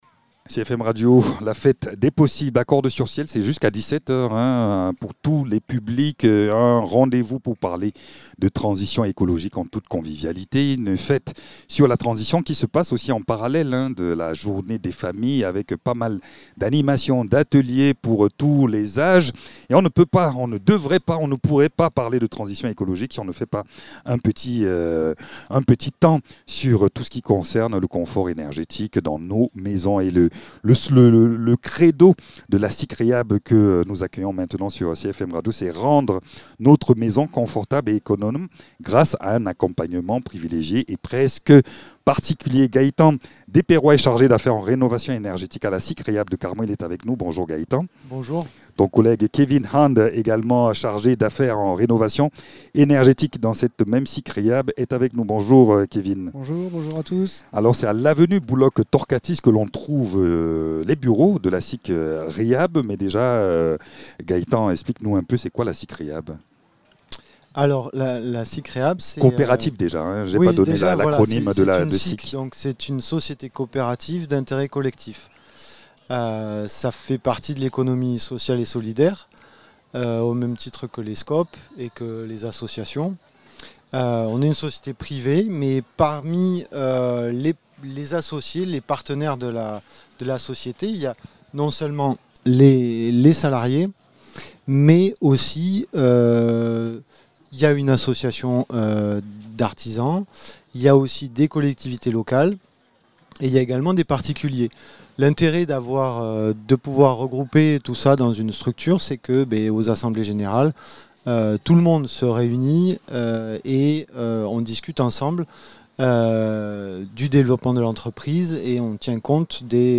La SCIC Rehab du Carmausin-Ségala s’engage pour la réhabilitation et la valorisation des bâtiments anciens, en alliant savoir-faire local et pratiques durables. Dans cette interview, l’équipe revient sur ses missions, ses projets et l’importance de concilier patrimoine, économie circulaire et développement territorial.